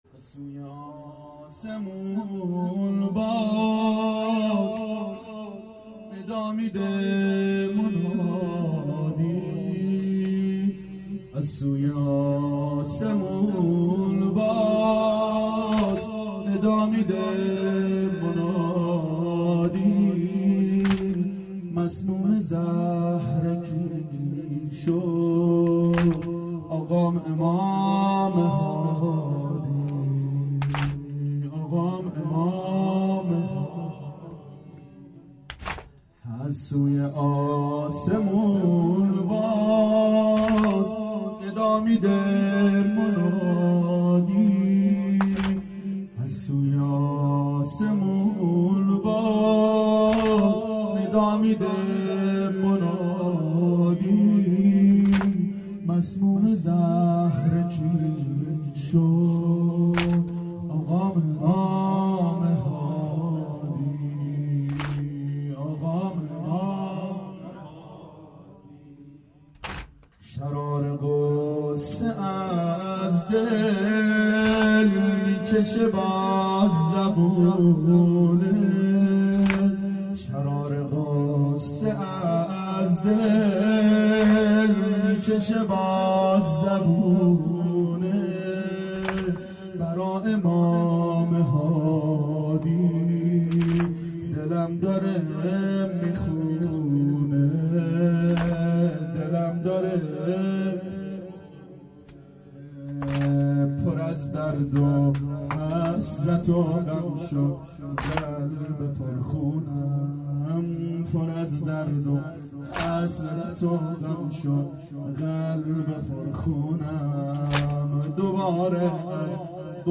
شب شهادت امام هادی علیه السلام 92 محفل شیفتگان حضرت رقیه سلام الله علیها